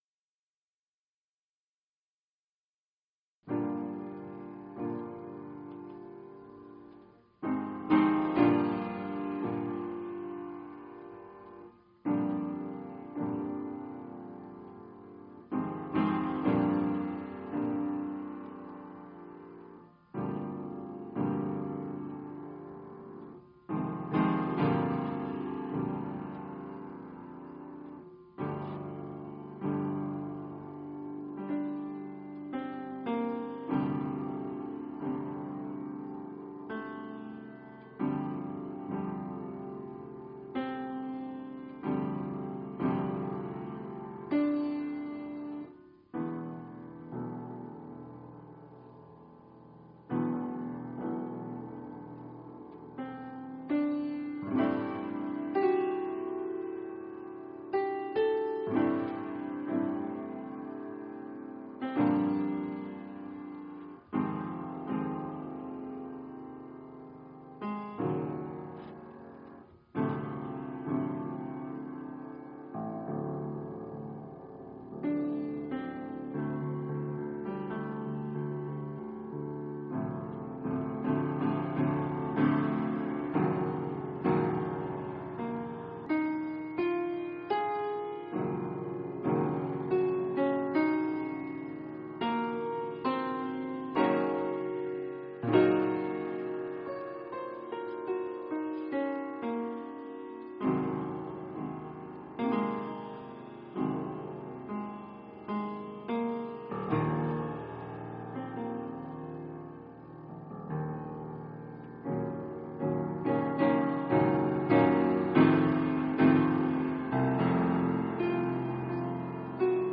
For violin, or corno inglese or lower oboe & piano
Versie voor piano / Version for piano